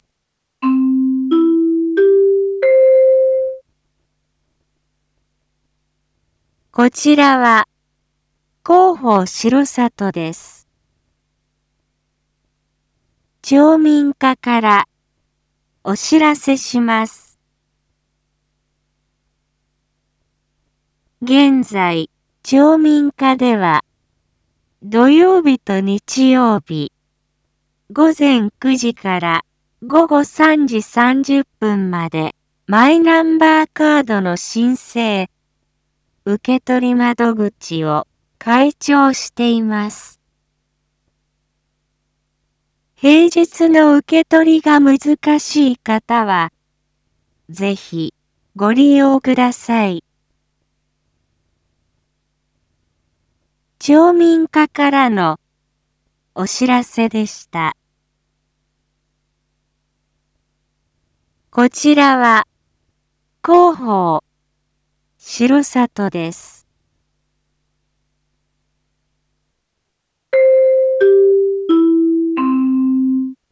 Back Home 一般放送情報 音声放送 再生 一般放送情報 登録日時：2023-03-25 19:01:15 タイトル：R5.3.25 19時放送分 インフォメーション：こちらは、広報しろさとです。